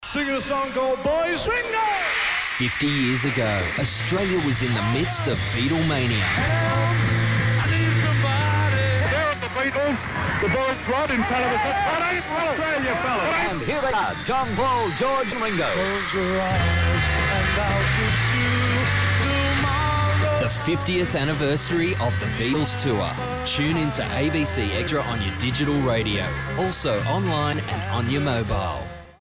Here’s a radio promo for the station:
beatles-extra-promo.mp3